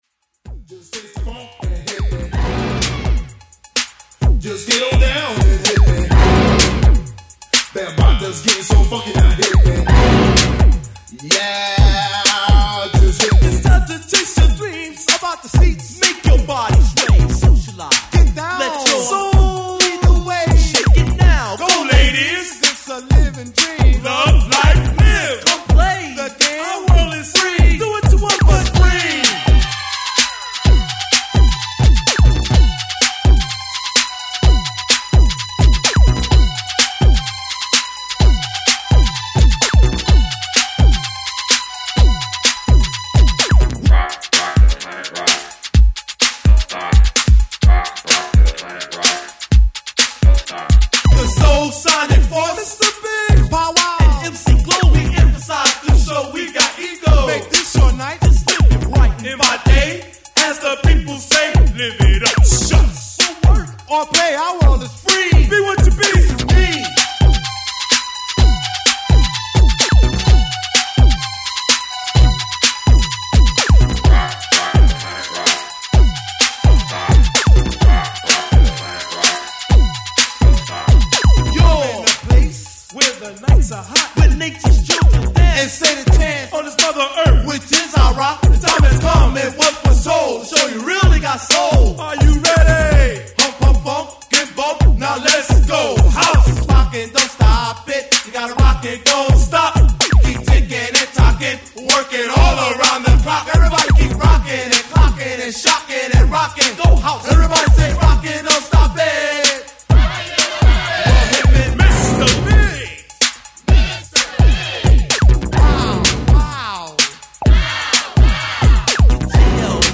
Accessoirement ils ont joué quatre titres en live, raconté des histoires marrantes de tournée et expliqué l’influence de la pop sur leur musique.